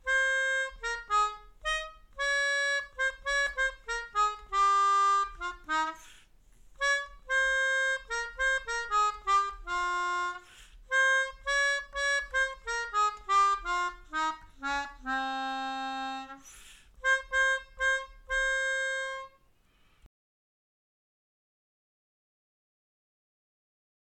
Melodica / Pianica / Airboard